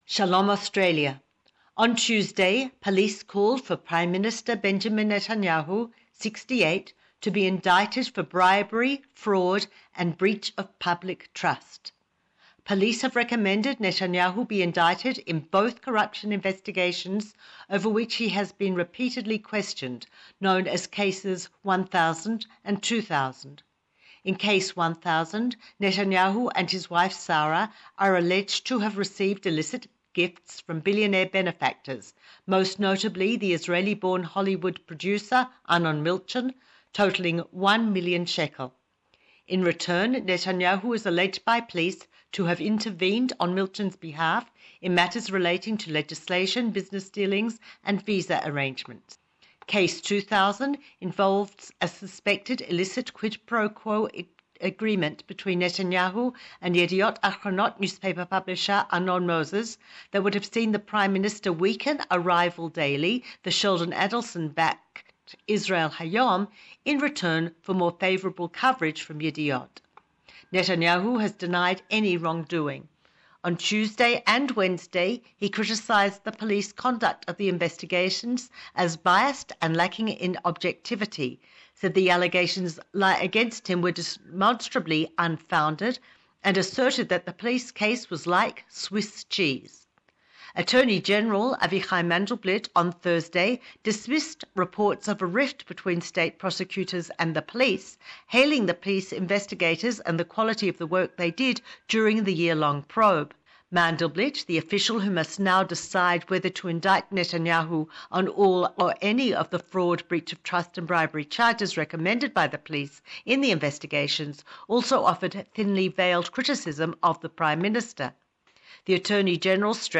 Find out in depth the latest news from Jerusalem.